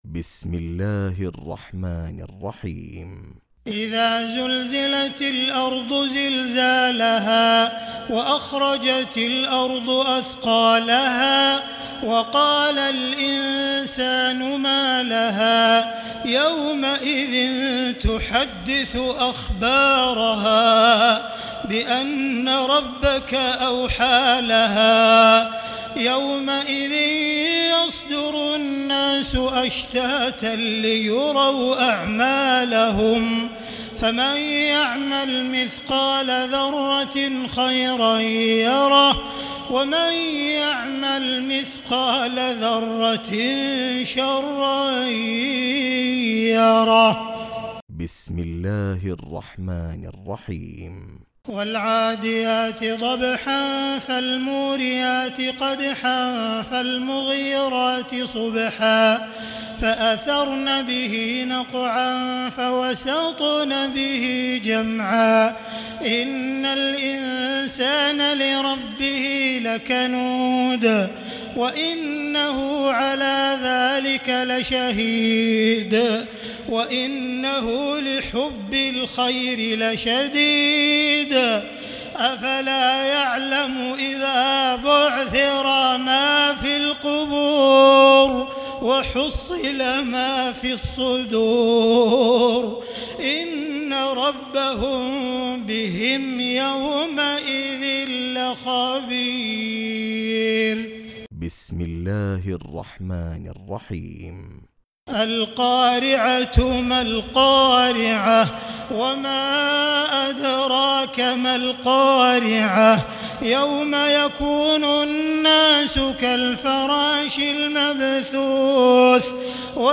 تراويح ليلة 29 رمضان 1439هـ من سورة الزلزلة الى الناس Taraweeh 29 st night Ramadan 1439H from Surah Az-Zalzala to An-Naas > تراويح الحرم المكي عام 1439 🕋 > التراويح - تلاوات الحرمين